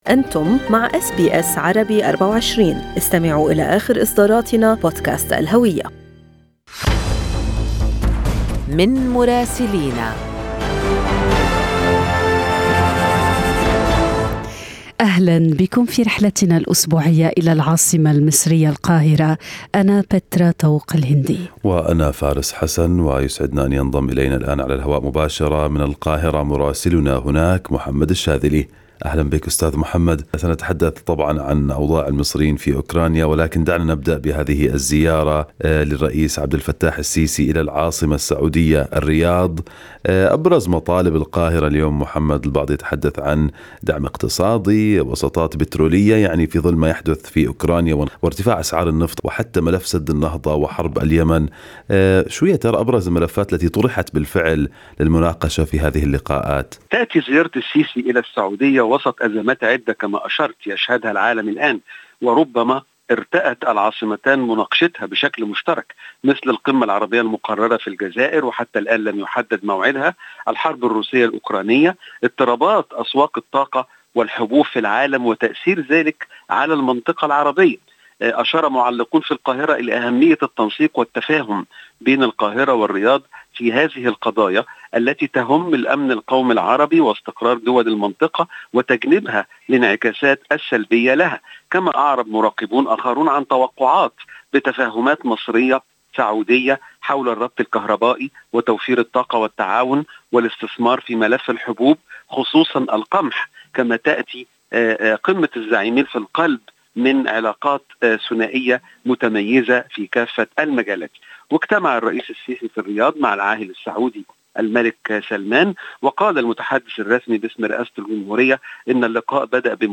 من مراسلينا: أخبار مصر في أسبوع 9/3/2022